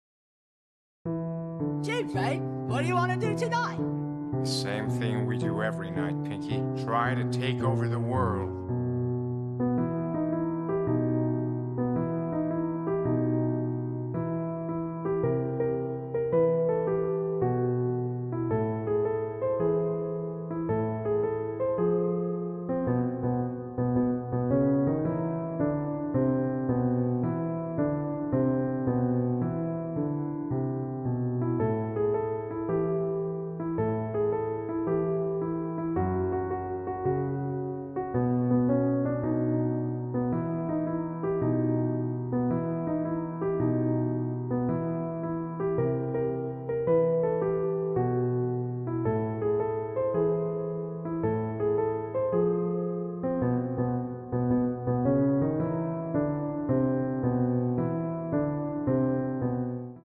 EASY Piano Tutorial